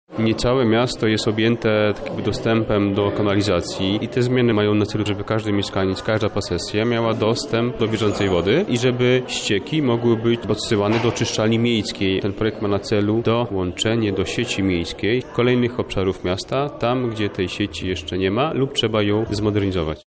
Nadal wiele części miasta nie ma dostępu do wodociągów i kanalizacji – mówi